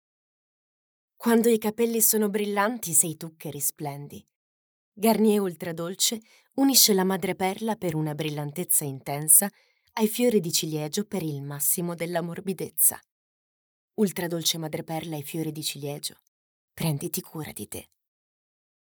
Attrice e speaker italiana. Una voce calda e avvolgente.
Sprechprobe: Werbung (Muttersprache):
A deep and enclosing voice for your needs.